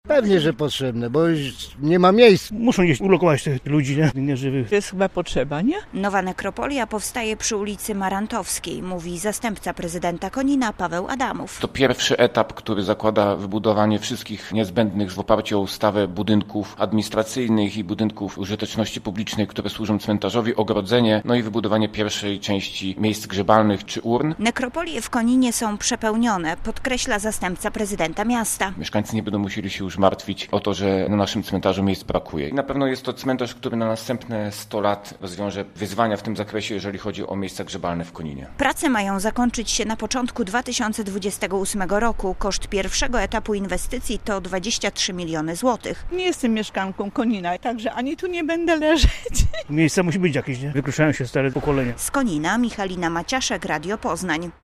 - Nowa nekropolia powstaje przy ulicy Marantowskiej - mówi zastępca prezydenta Konina Paweł Adamów.
A co na to mieszkańcy Konina?